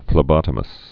(flĭ-bŏtə-məs)